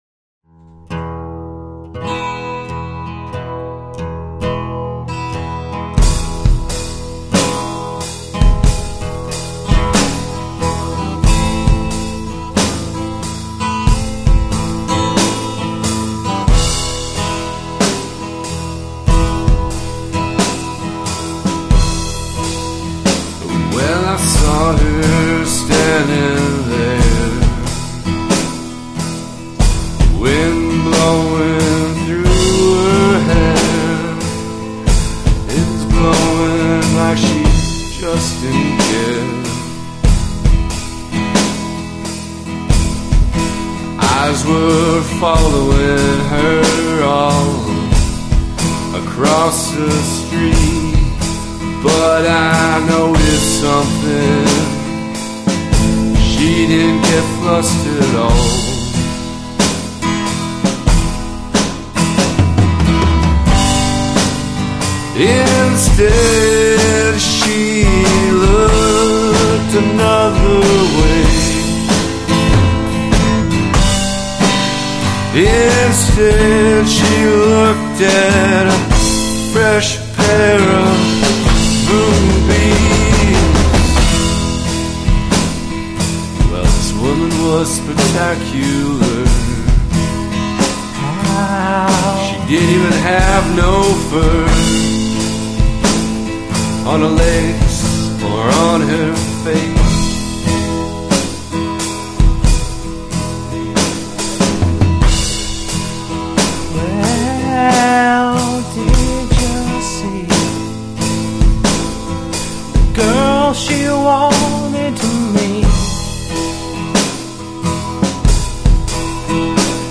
Acoustic Rock
Main Vocals
Acoustic Guitar
Drums